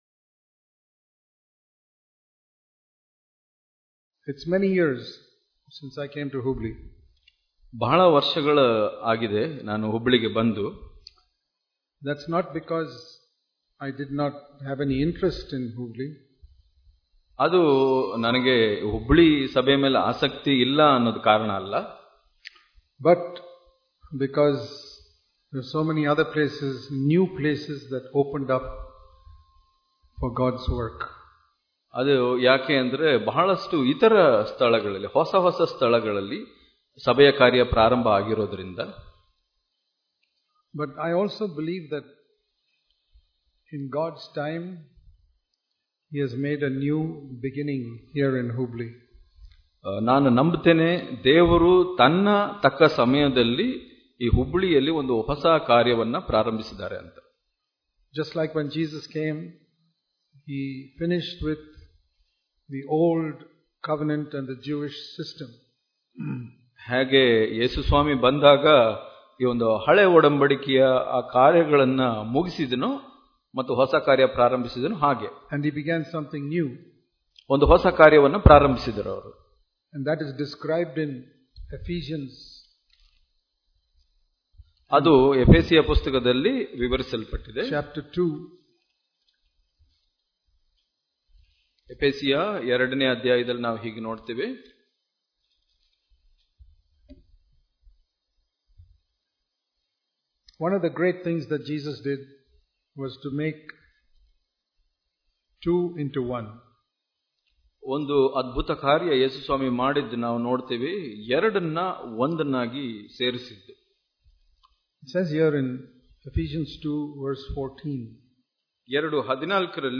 Hubli Conference 2018
Sermons